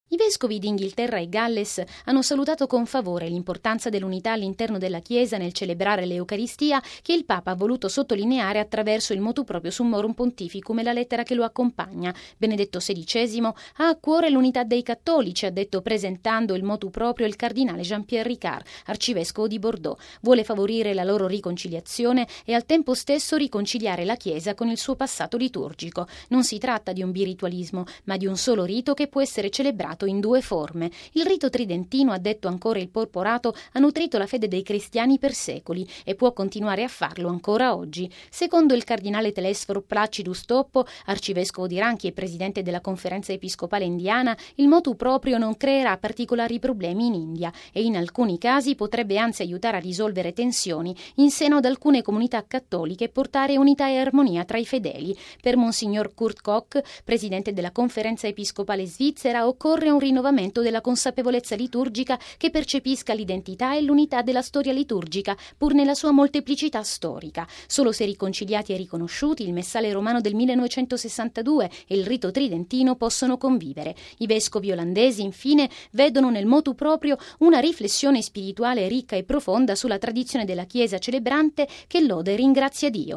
Per una carrellata sulle varie posizioni espresse dai vescovi europei, il servizio